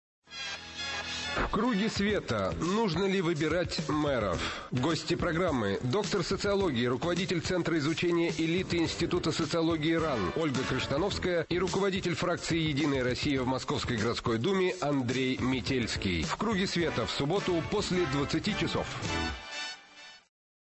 Аудио: анонс –